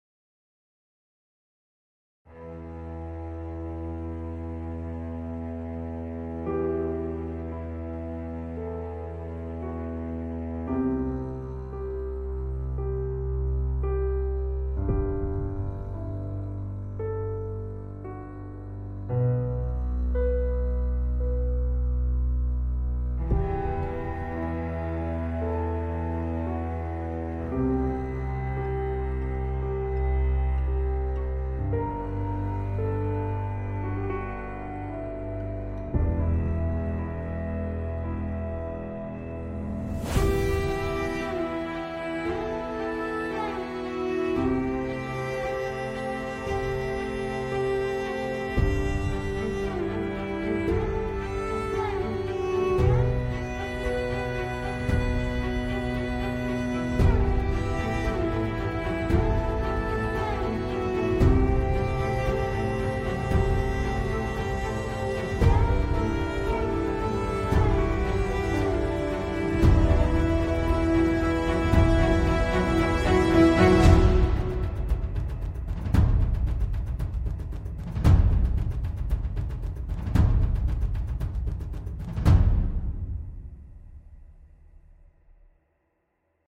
played by an orchestra